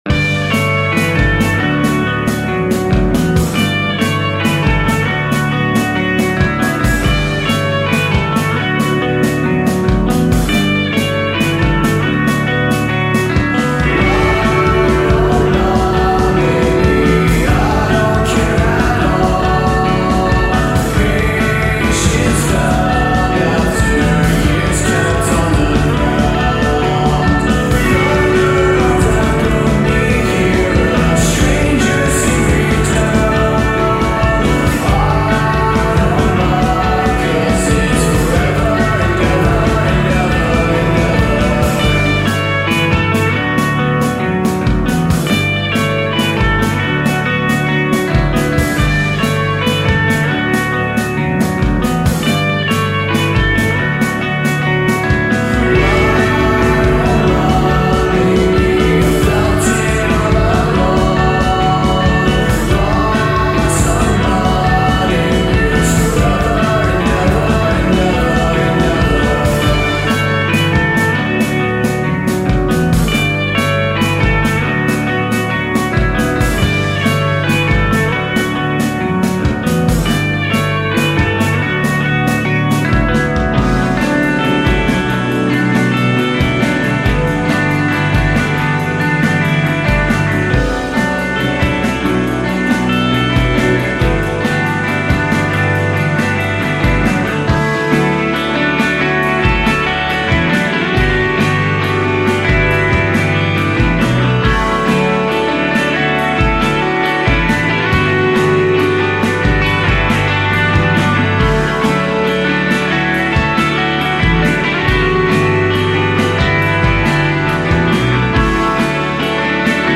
dreampop band
fashions beautiful sun-kissed dreampop